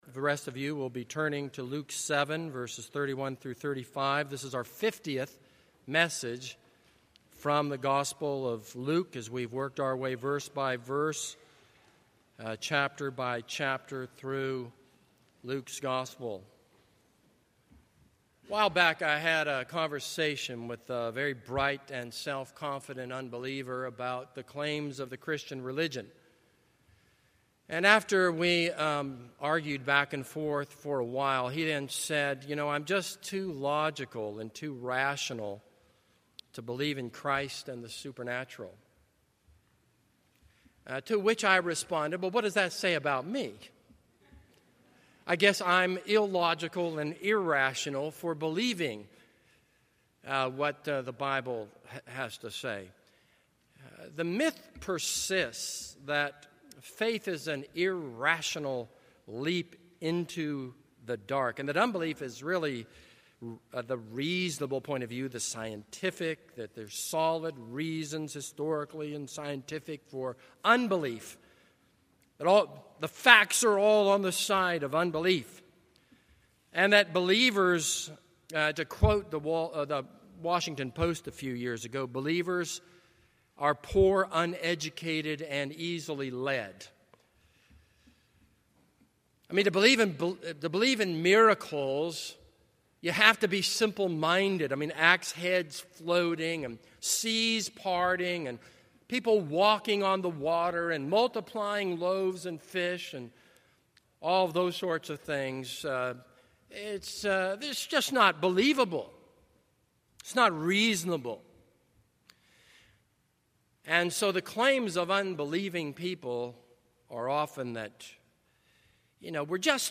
This is a sermon on Luke 7:31-35.